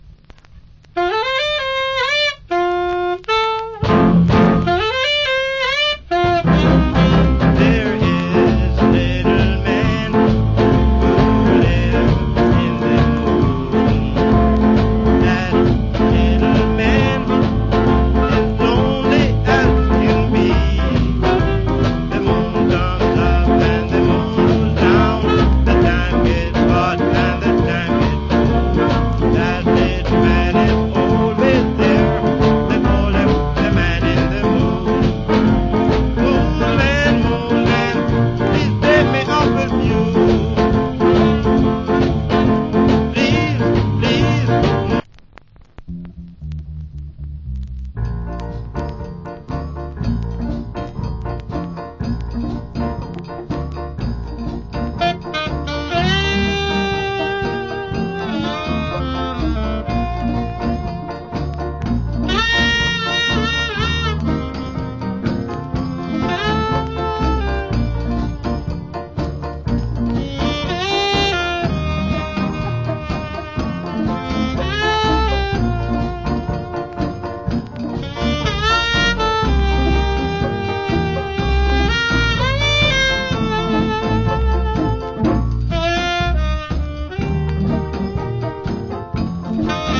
Good Ska Vocal.